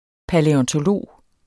Udtale [ palεʌntoˈloˀ ]